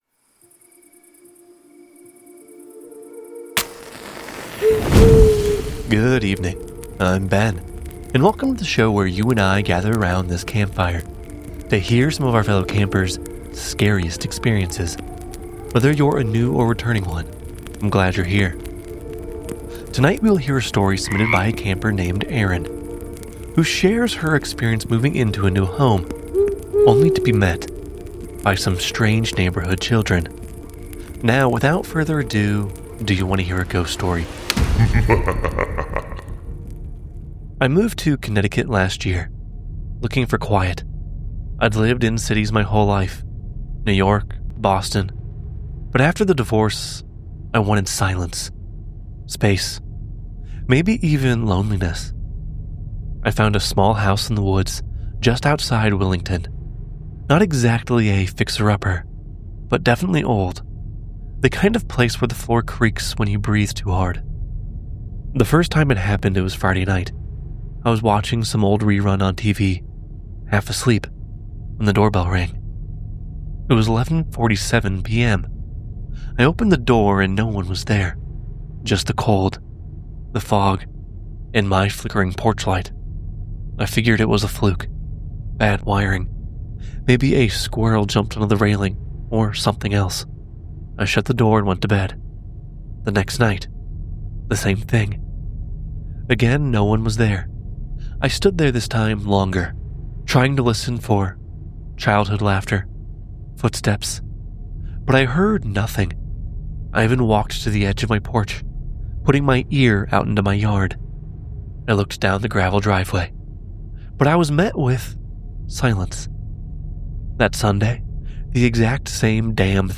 Narrated by:
Sound Design by: